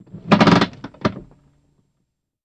BMW Parking Brake, Set Quickly